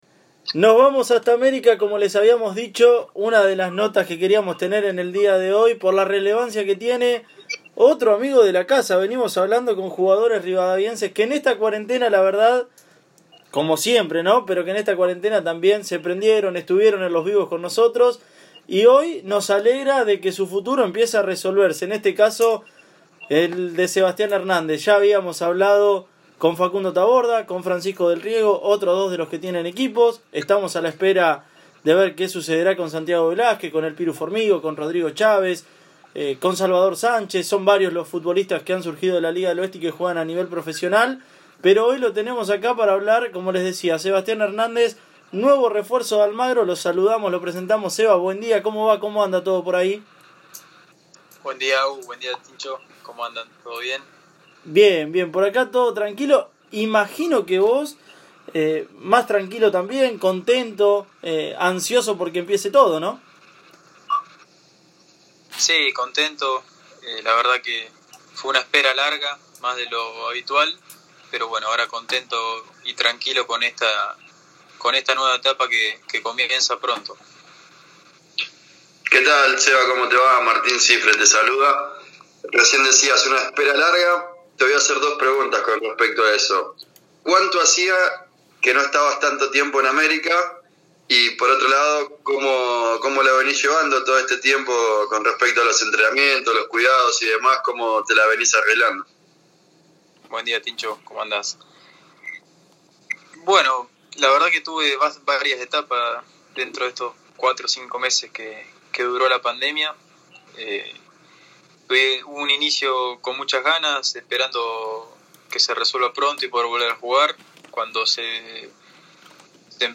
En nuestro programa radial, el marcador central se refirió al parate por la pandemia, las expectativas que tiene puestas en este nuevo desafío y lo que significará compartir plantel con otro rivadaviense.